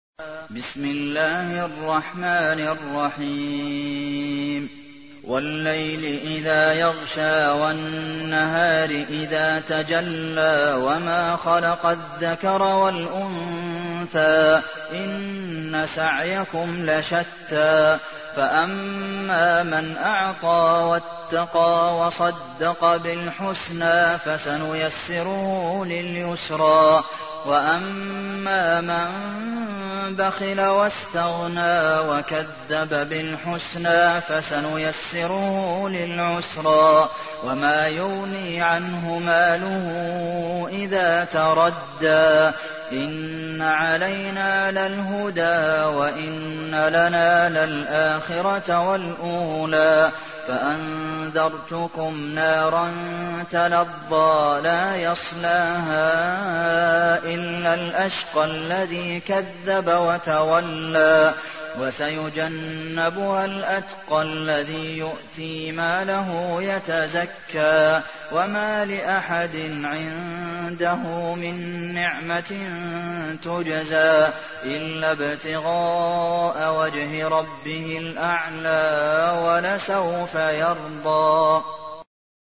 تحميل : 92. سورة الليل / القارئ عبد المحسن قاسم / القرآن الكريم / موقع يا حسين